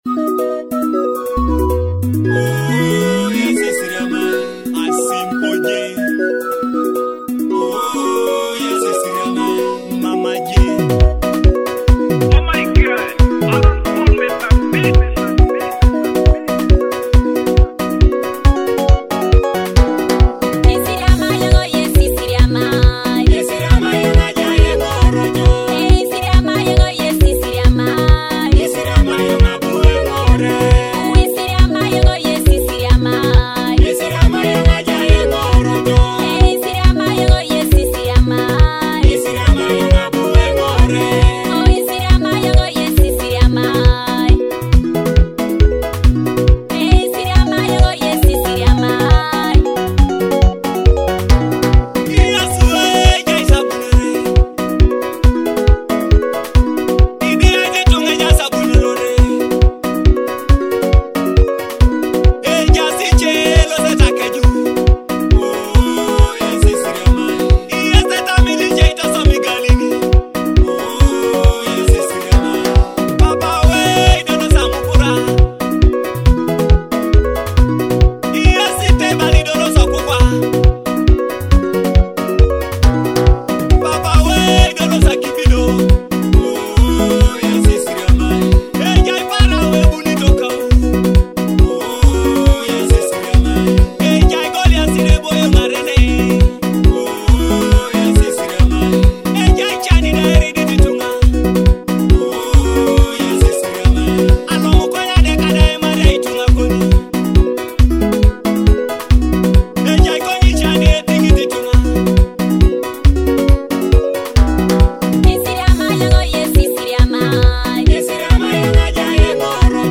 gospel praise & worship